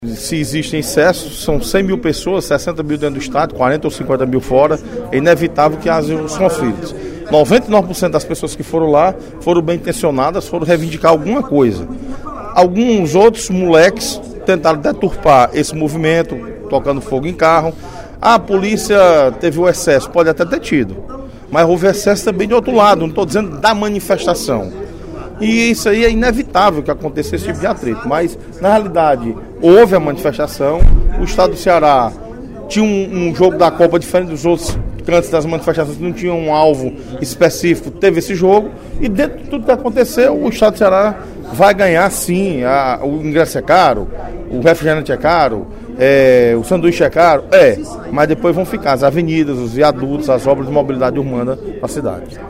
Em pronunciamento durante o primeiro expediente da sessão plenária desta quinta-feira (20/06), o deputado Osmar Baquit (PSD) parabenizou a ação policial nas manifestações que ocorreram ontem (19/06), nas proximidades da Arena Castelão.